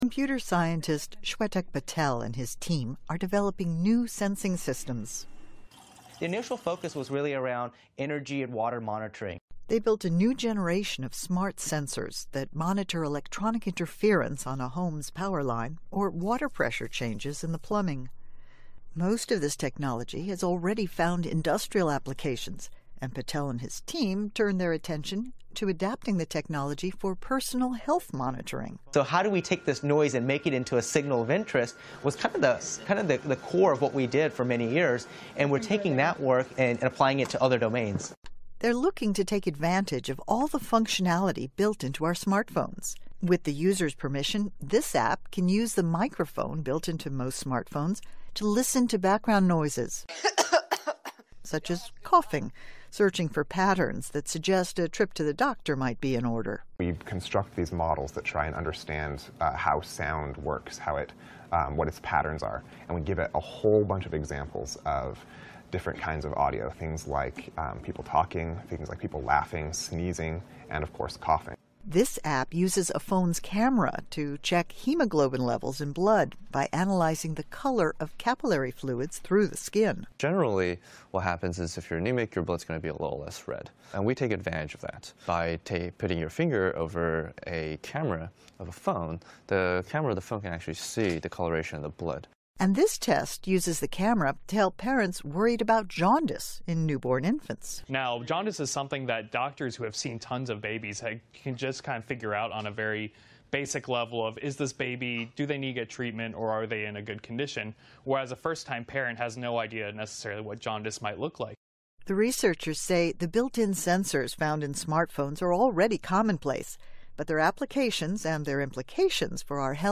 You will hear an Interview/Lecture.